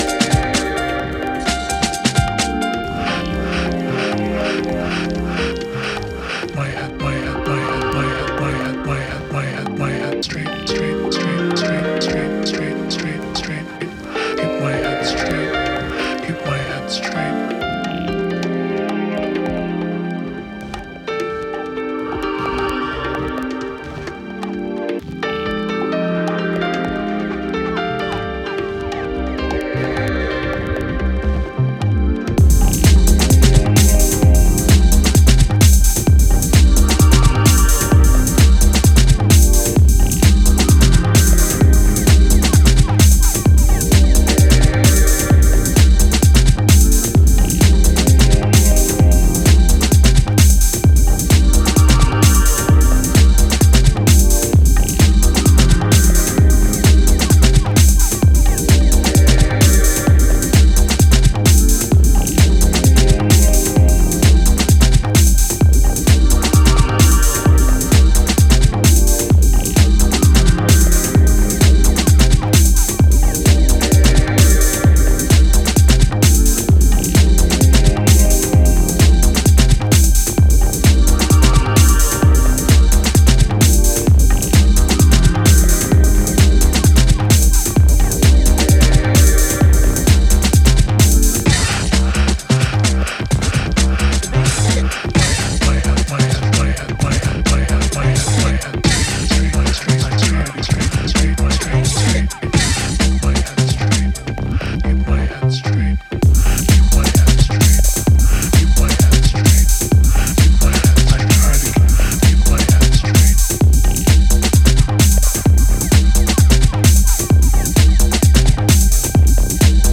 widens out textures with deep organic rhythms